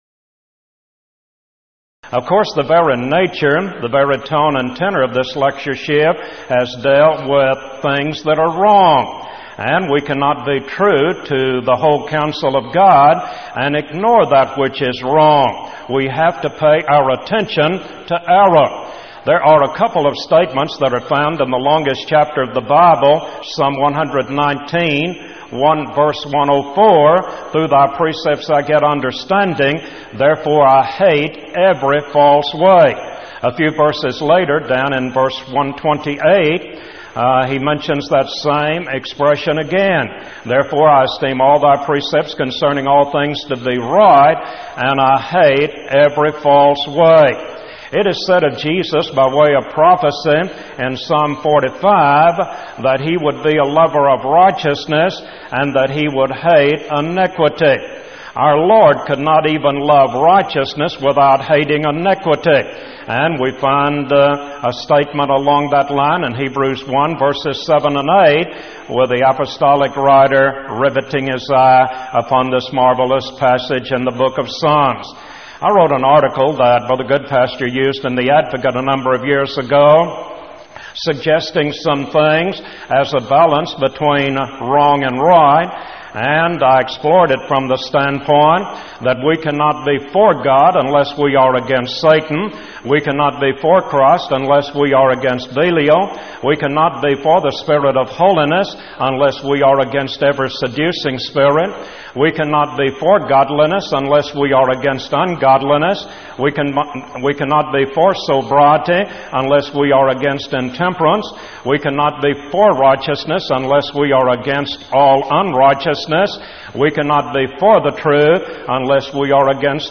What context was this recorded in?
Event: 1997 Power Lectures